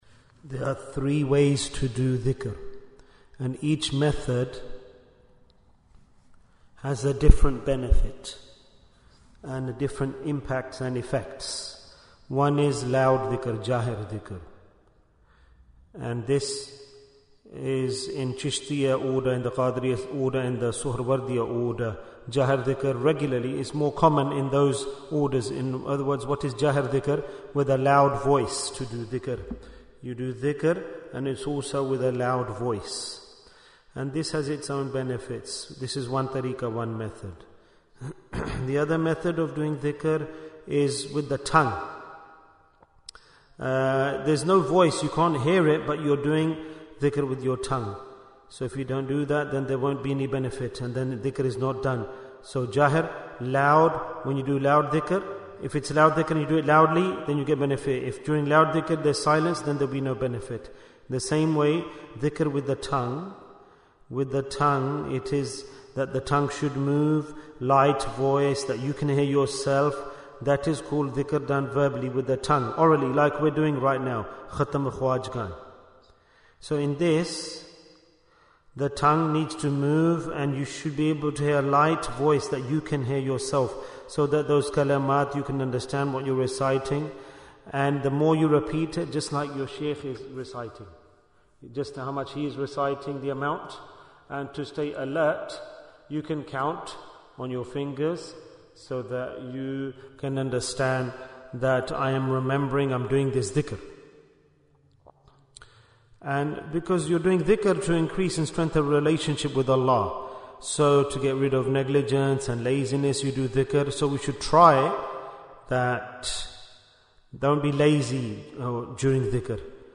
Jewels of Ramadhan 2025 - Episode 6 Bayan, 13 minutes5th March, 2025